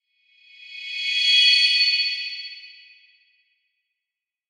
Шепот света кристалла